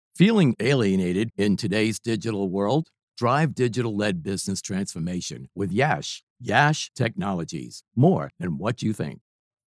Technology Demo.mp3
New England, Southern.
Middle Aged